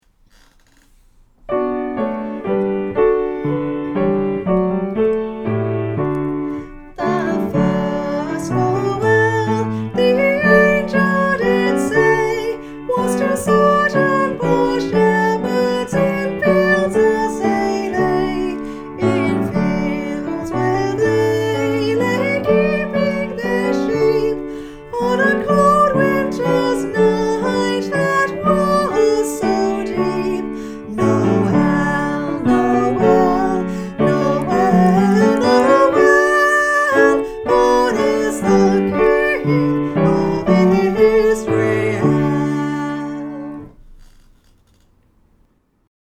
Vox Populi Choir is a community choir based in Carlton and open to all comers.
The_First_Nowell-Bass.mp3